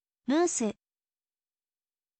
muusu, มือสึ